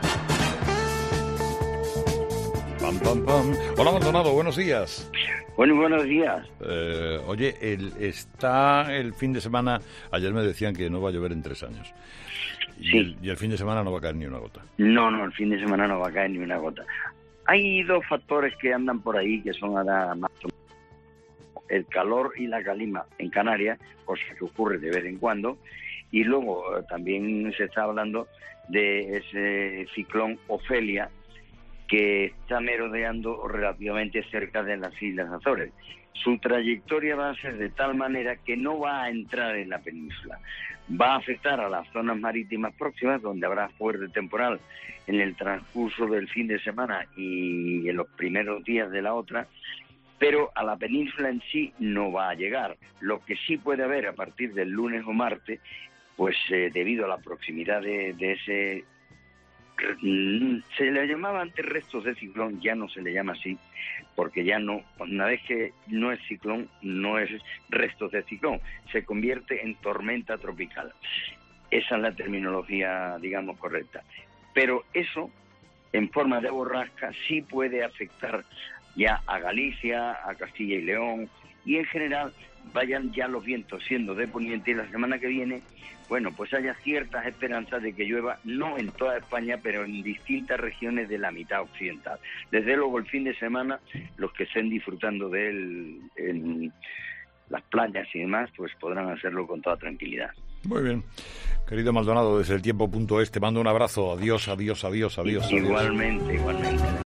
José Antonio Maldonado predice, como cada viernes, el tiempo para los próximos días.